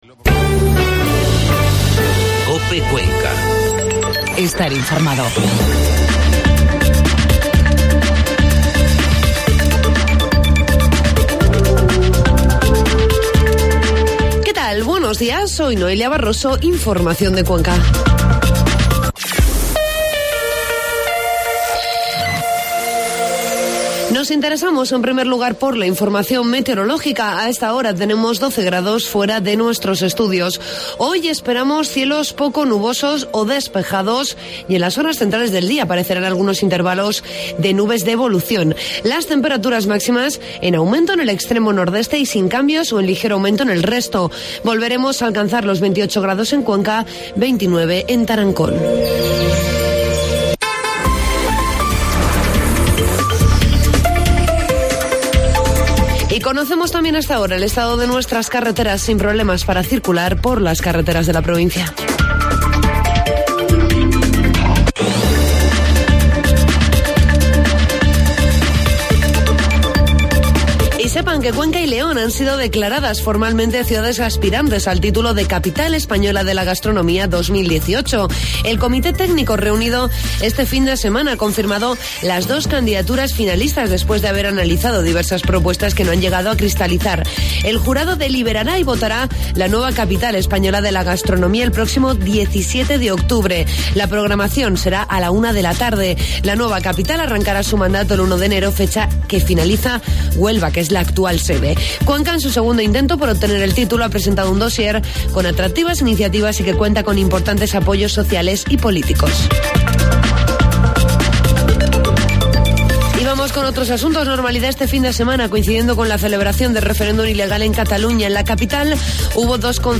Informativo matinal COPE Cuenca 3 de octubre